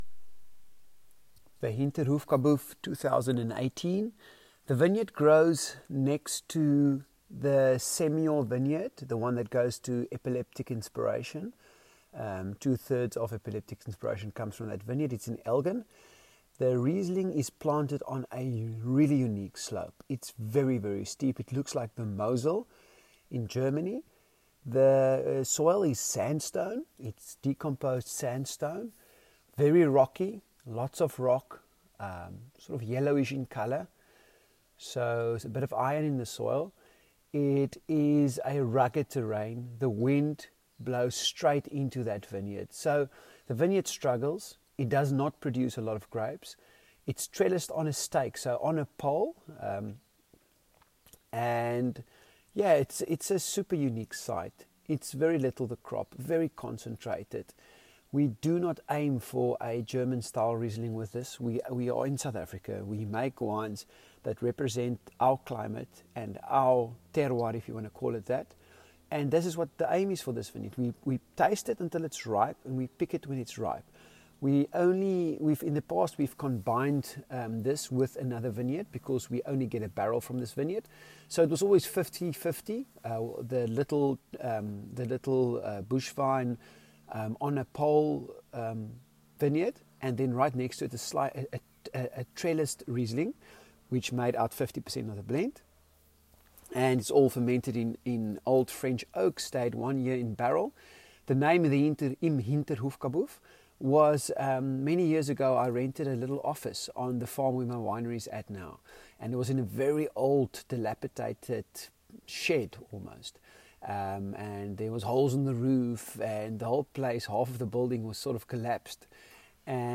Voice Recording: